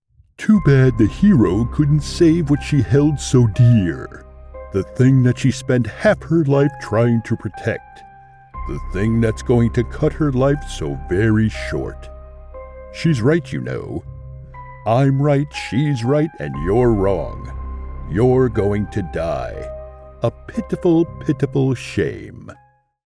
I have a deep, rich, resonant voice perfect for any type of voice over you need.
Animated video Evil character, Villain, Anime
A Source-Connect equipped professional Whisper Room sound booth.